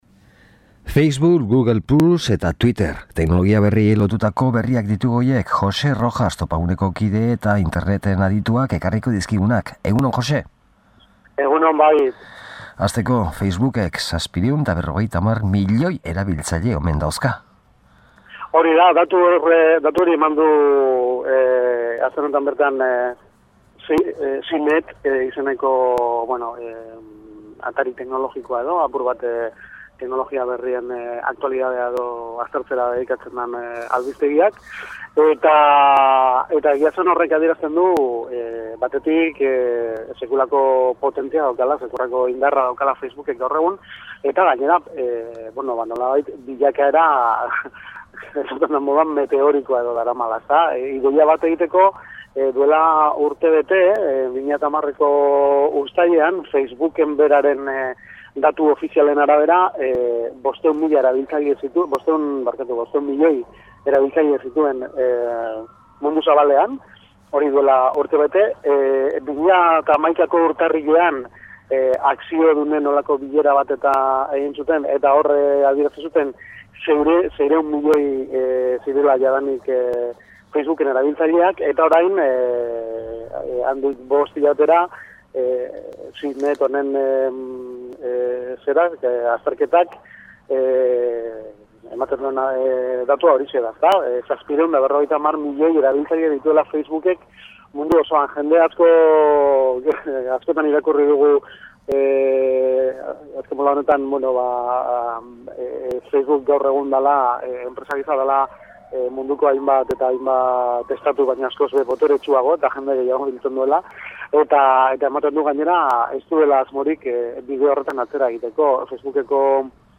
SOLASLDIA